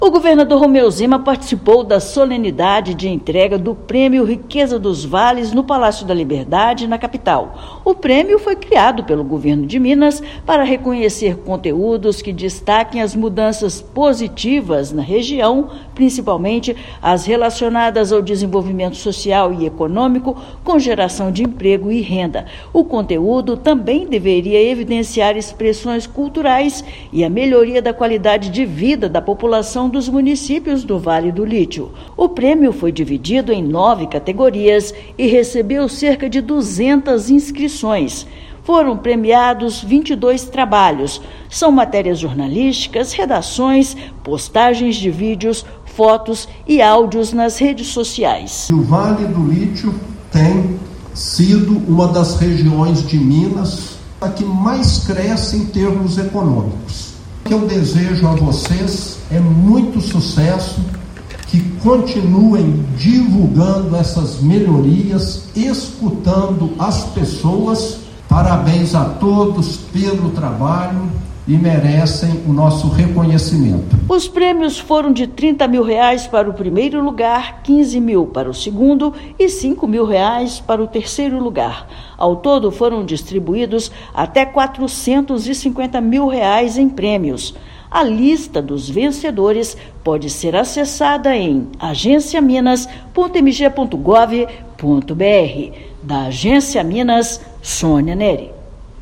[RÁDIO] Governo de Minas participa da solenidade de entrega do prêmio Riquezas dos Vales
Premiação reconhece histórias transformadas pelas ações do Vale do Lítio no Jequitinhonha, Mucuri e Norte de Minas. Ouça matéria de rádio.